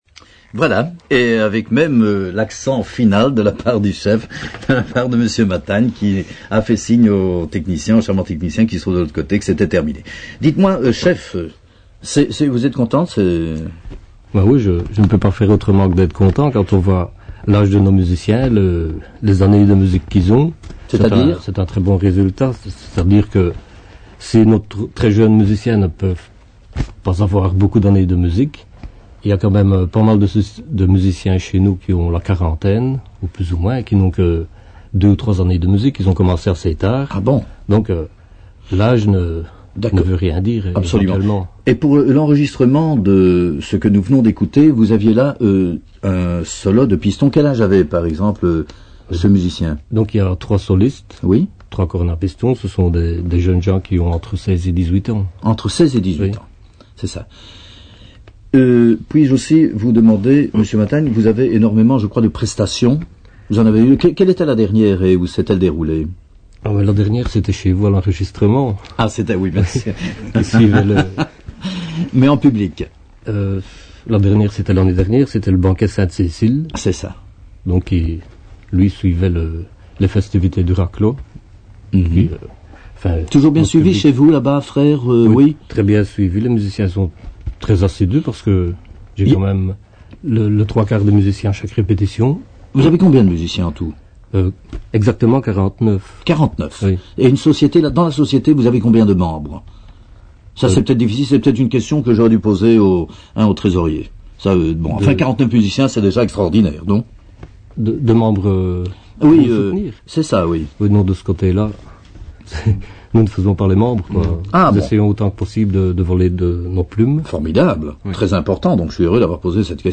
Et, avant de découvrir avec beaucoup plus de profondeur chacun des personnages que nous allons rencontrer ce soir, je vous propose une seconde interprétation, et c'est " Bugler's Holiday ", un solo de pistons.